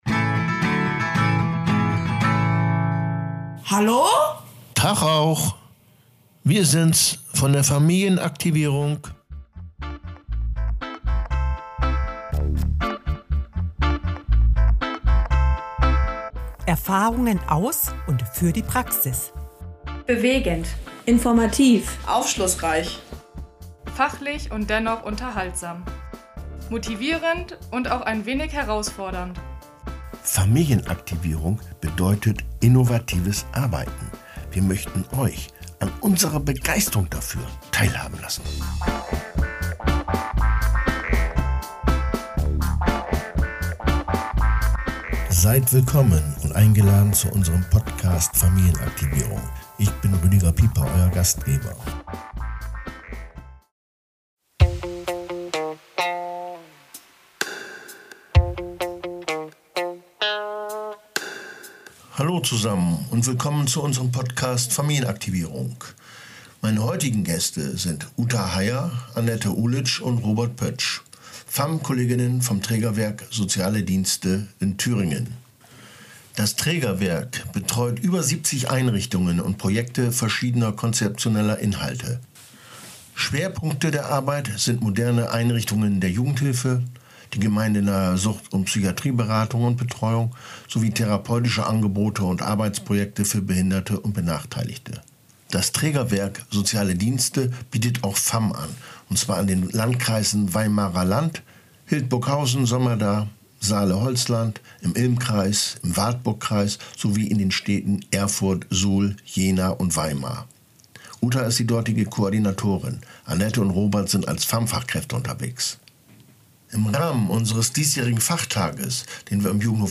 Wie gestaltet sich die familienaktivierende Praxis in Thüringen? Drei Fachkräfte im Austausch über ihre Erfahrungen in der Fallarbeit und über die dortigen Rahmenbedingungen.